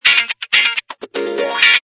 Wah-Wah.mp3